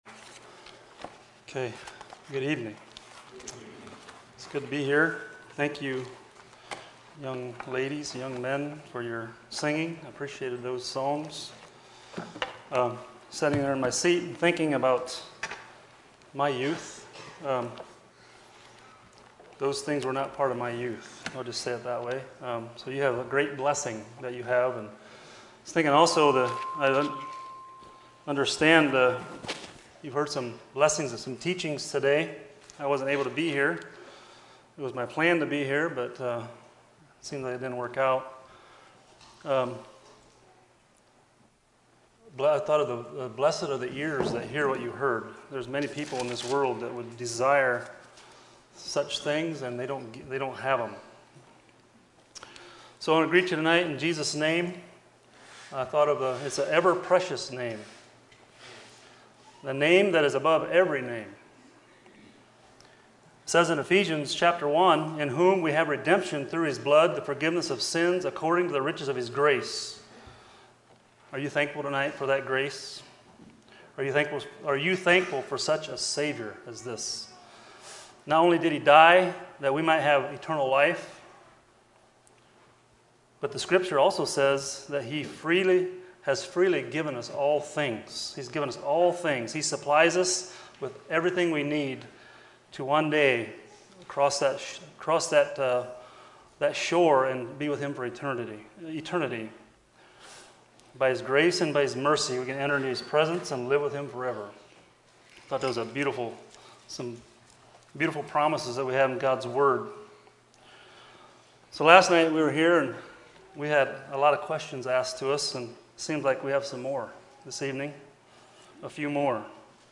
2024 Youth Meetings , Youth Meeting Messages